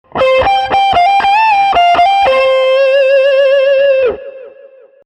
E-Gitarre
Zupfinstrument
Electric-guitar.mp3